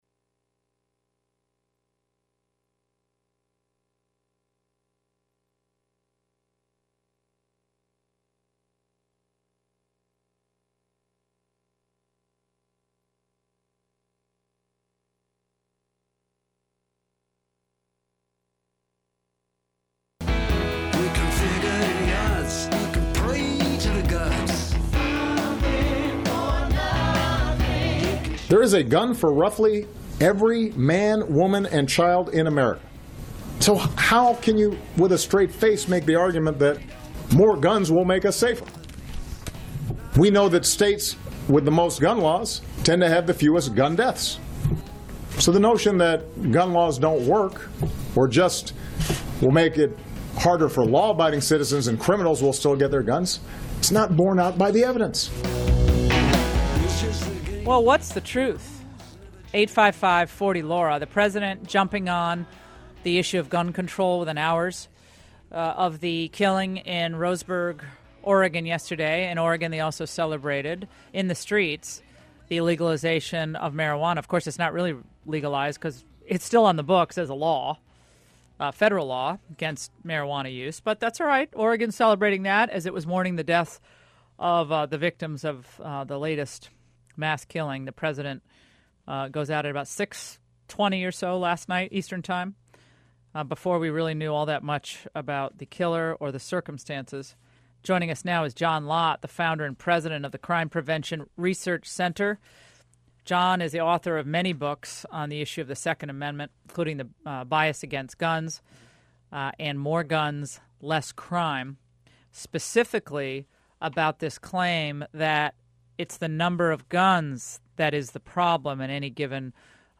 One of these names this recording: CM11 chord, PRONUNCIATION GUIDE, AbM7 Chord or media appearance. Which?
media appearance